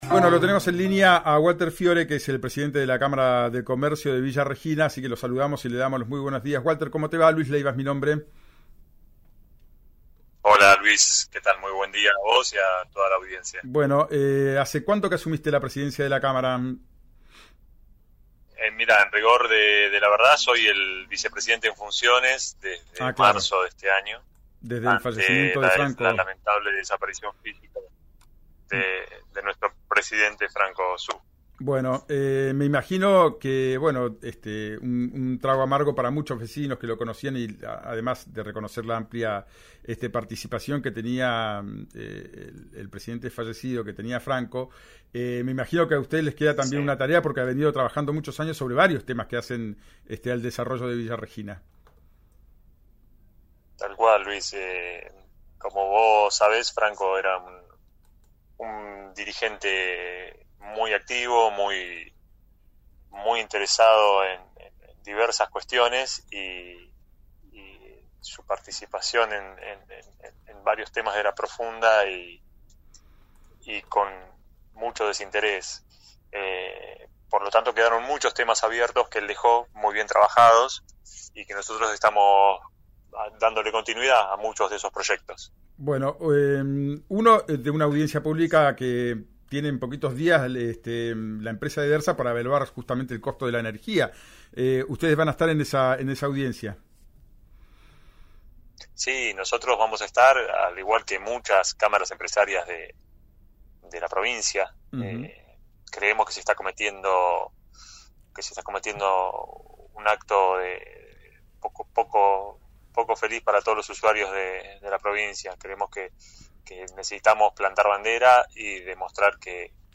dialogó con el programa “Ya es tiempo” por RÍO NEGRO RADIO y mencionó que en las últimas dos semanas las familias comenzaron a organizarse y armar un movimiento más fuerte.